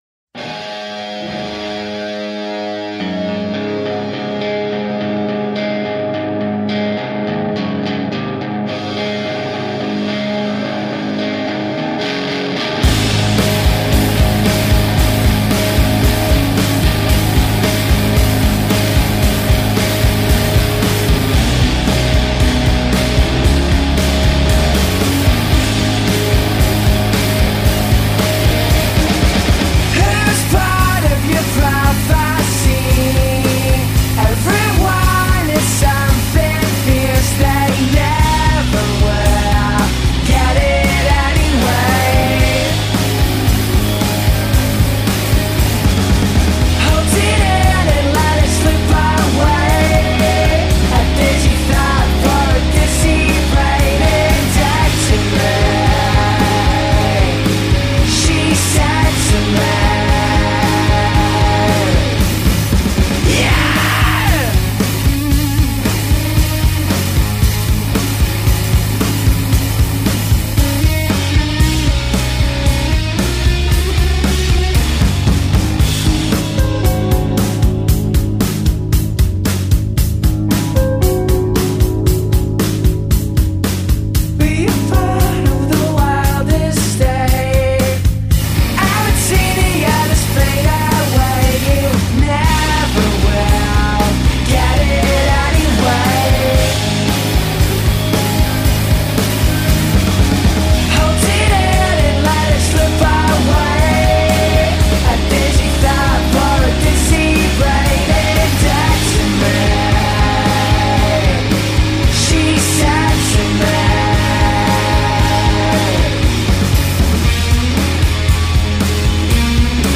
alt-rock band